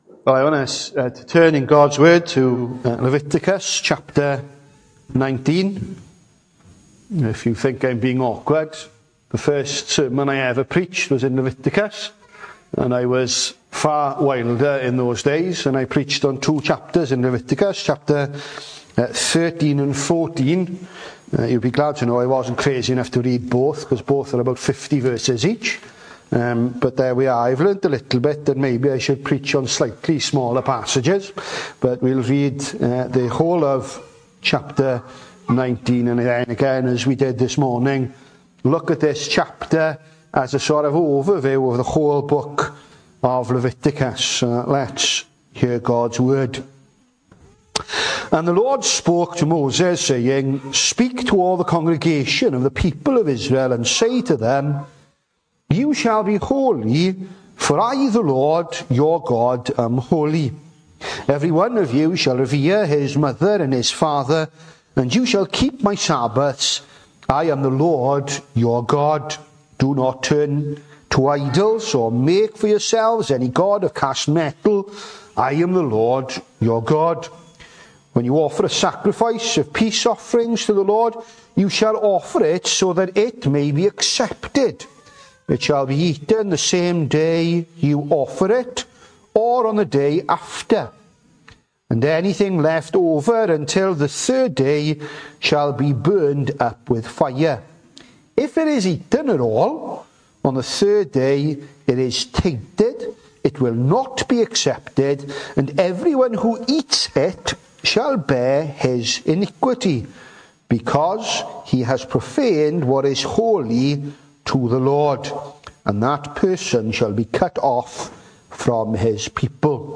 The 23rd of November saw us hold our evening service from the building, with a livestream available via Facebook.
Sermon